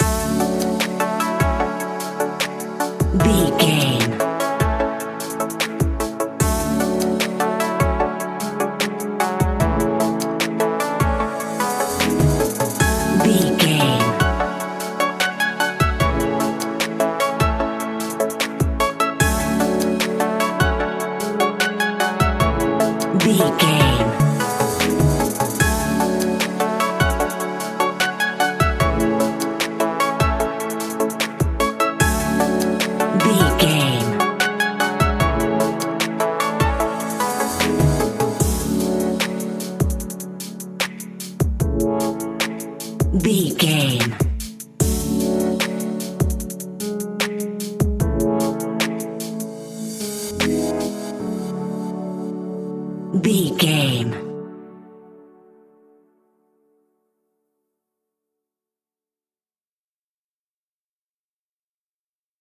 Ionian/Major
hip hop
chilled
laid back
groove
hip hop drums
hip hop synths
piano
hip hop pads